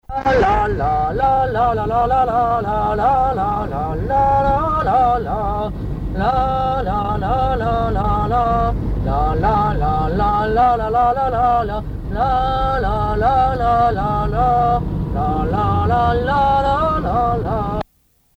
Air de danse chantée
Note chanté en tralala, entendu sans enregistrer et rechanté
Localisation Cancale (Plus d'informations sur Wikipedia)
Usage d'après l'analyste gestuel : danse ;
Genre brève